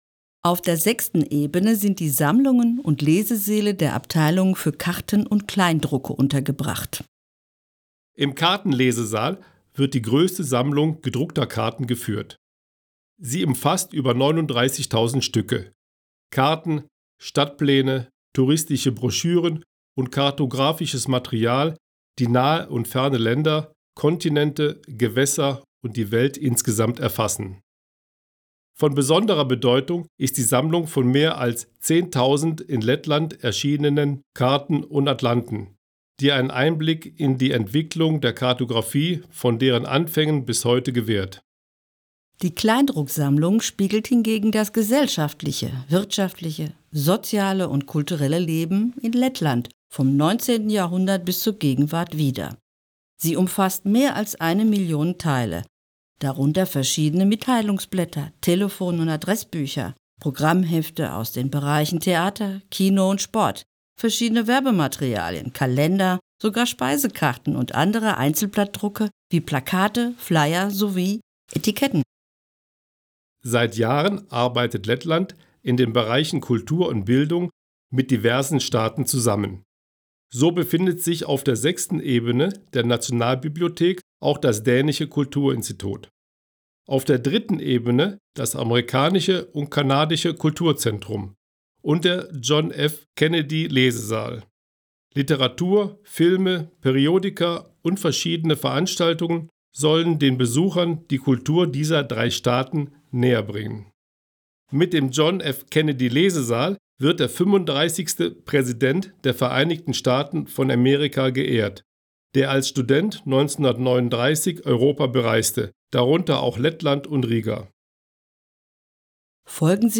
balss aktieris
Tūrisma gidi